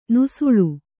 ♪ nusuḷu